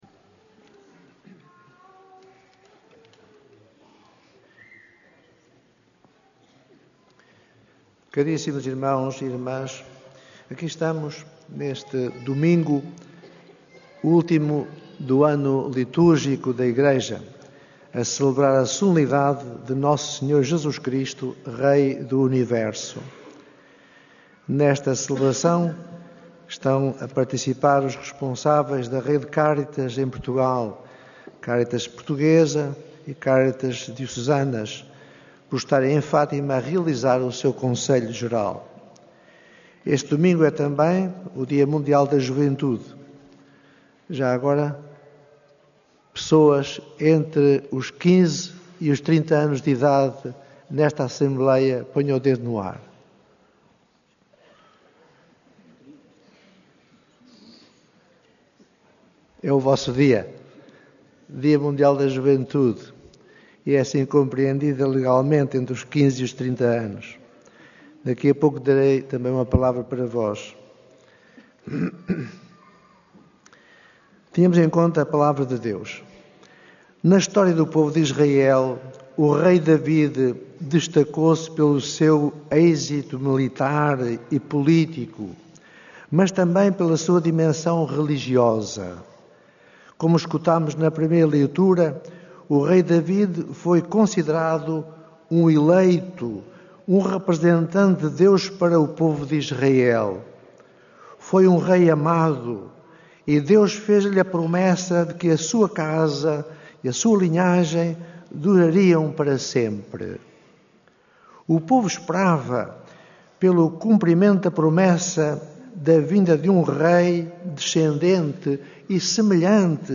Esta manhã, na missa que celebrou a Solenidade de Nosso Senhor Jesus Cristo, Rei do Universo, D. José Traquina sublinhou a importância de uma ação coordenada e solidariedade em prol dos mais vulneráveis, exortando os peregrinos reunidos na Basílica da Santíssima Trindade a promover o amor e a generosidade.
Na homilia que proferiu, o bispo de Santarém e presidente da Comissão Episcopal da Pastoral Social e Mobilidade Humana dirigiu-se em particular aos responsáveis da rede Cáritas em Portugal, ali presentes no âmbito da Campanha 10 milhões de Estrelas pela Paz.